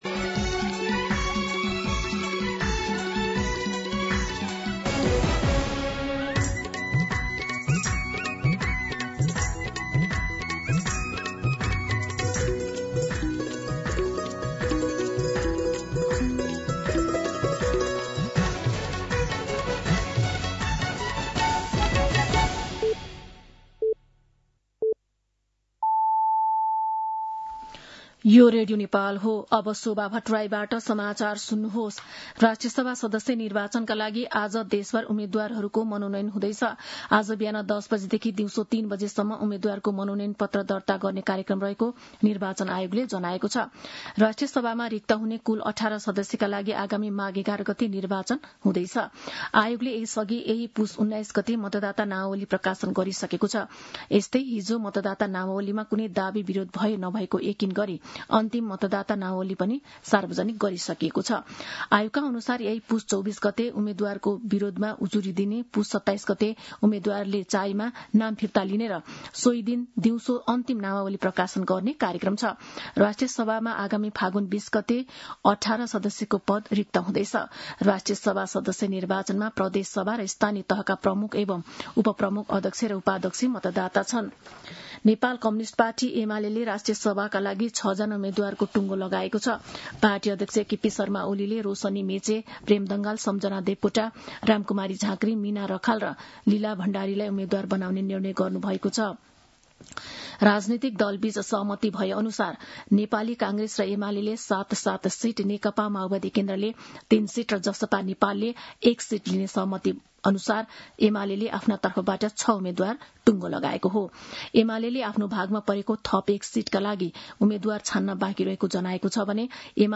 दिउँसो १ बजेको नेपाली समाचार : २३ पुष , २०८२
1-pm-Nepali-News-3.mp3